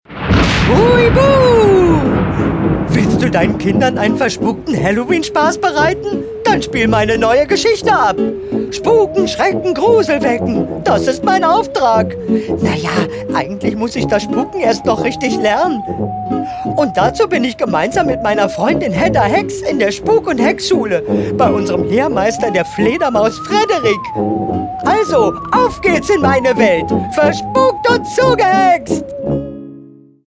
Neue Hörspielserie bei EUROPA